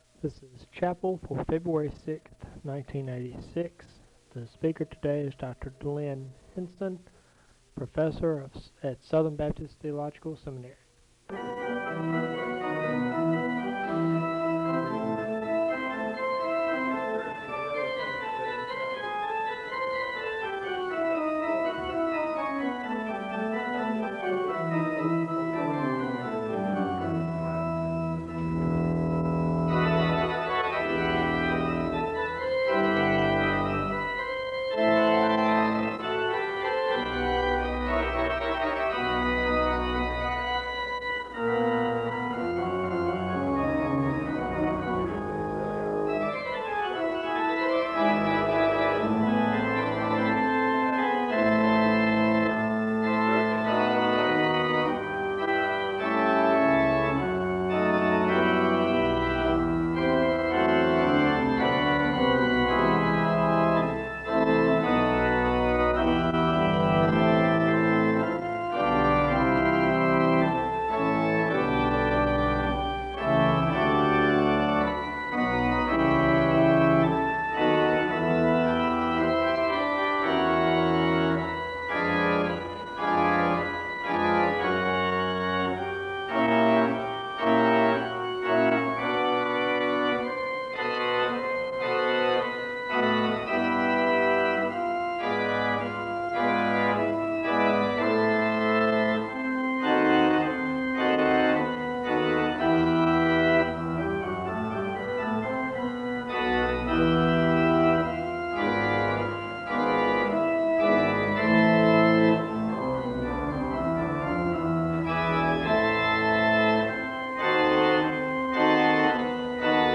The service begins with organ music (0:00-2:24). There is a word of prayer (2:25-4:22).
An anthem is sung by the choir (6:59-9:20).